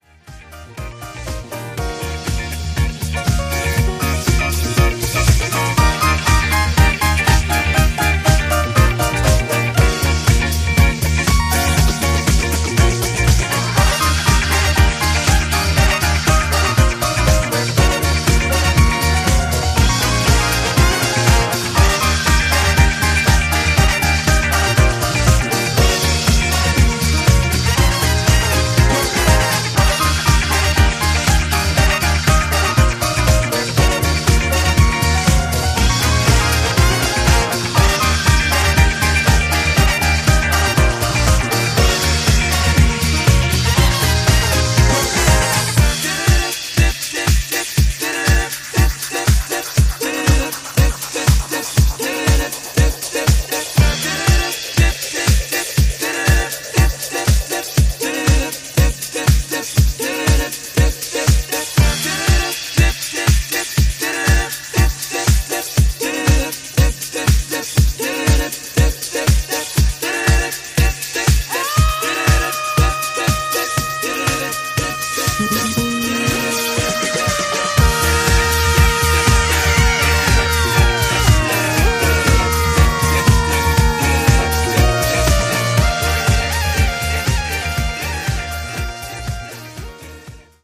Two bona fide classic 70s cuts reworked for now.